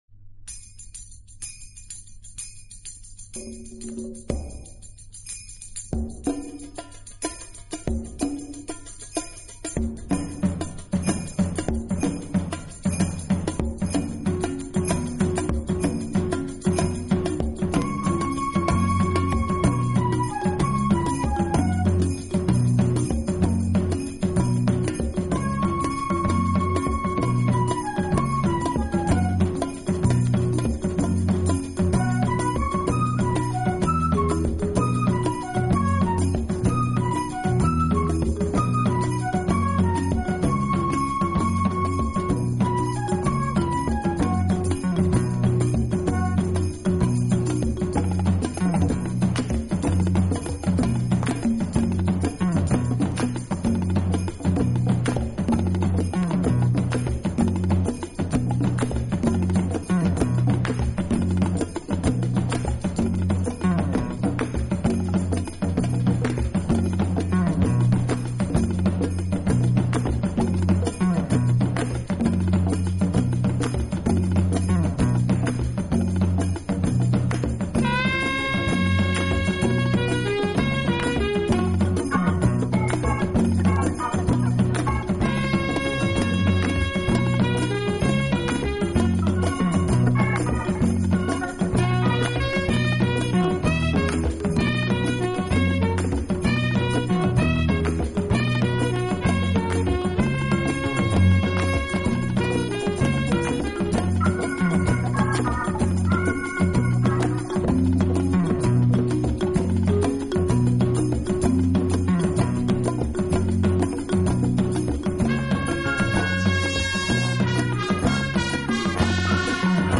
以擅长演奏拉丁美洲音乐而著称。演奏轻柔优美，特別是打击
乐器的演奏，具有拉美音乐独特的韵味。